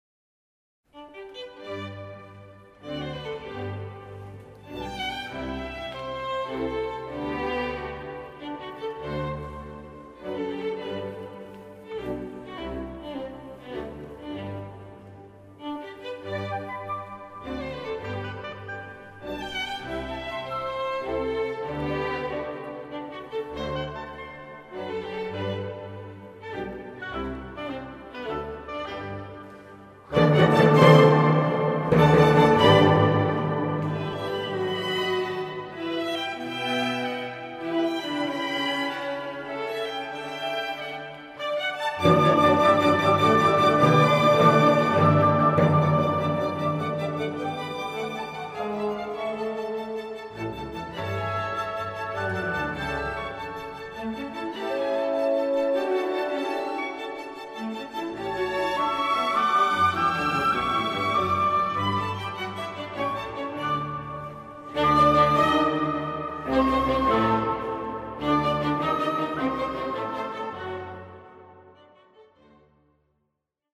Sopran Geboren in Wien
Alt Geboren in Villach
Tenor  Geboren in Heidenreichstein.
(Bass)
Flöte Geboren in Taipei
Gitarre Geboren 1975 in Graz.